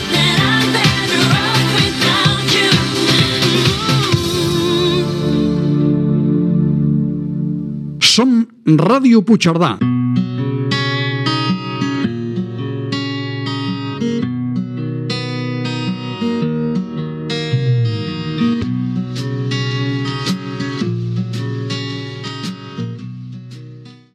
Música i indicatiu de l'emissora.